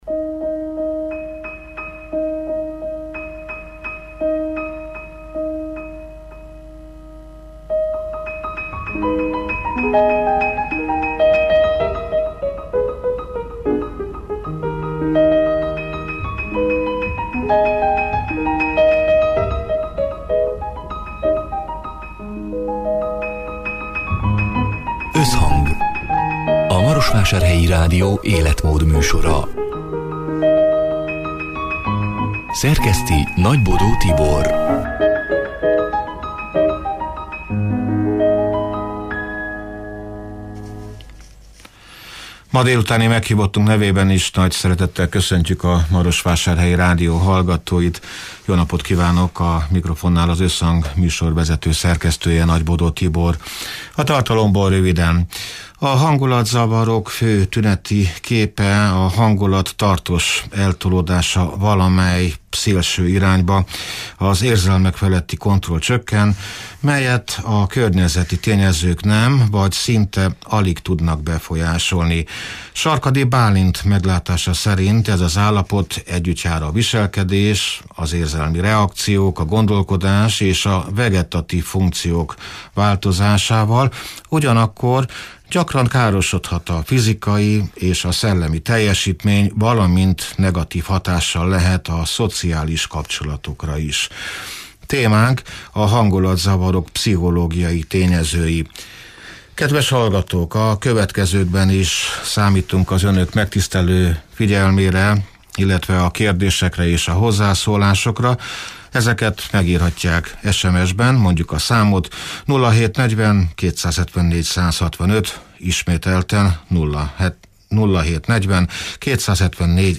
(elhangzott: 2024. november 20-án, szerdán délután hat órától élőben)